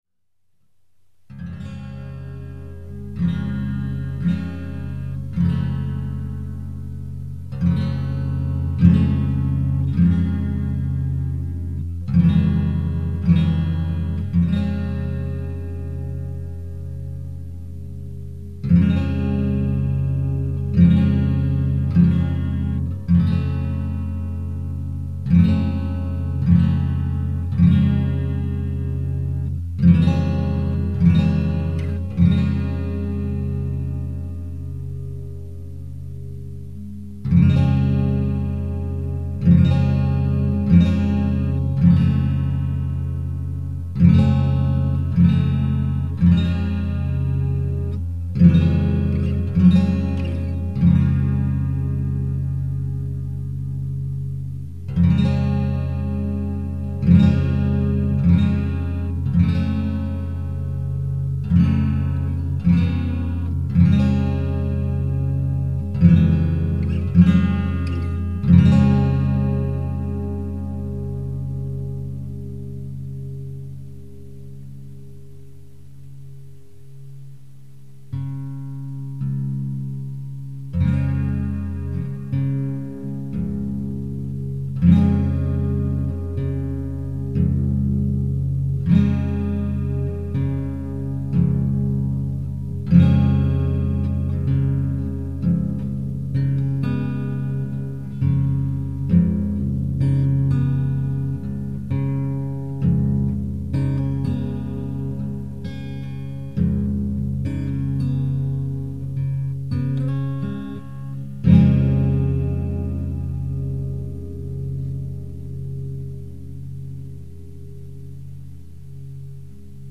Or, more accurately, all acoustic guitar.